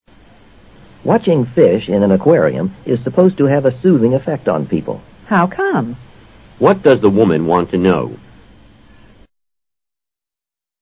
男方说观看鱼缸中的鱼使人感到很轻松。女方对此说感到好奇，提出疑问。
托福听力小对话